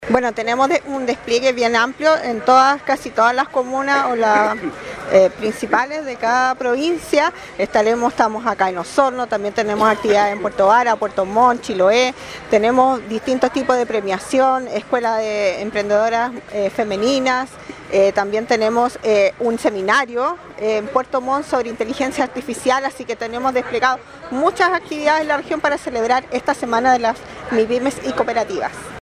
La directora regional de Sercotec, Jessica Kramm, manifestó que en la región de Los Lagos hay más de 30 actividades que se desarrollarán en distintas comunas.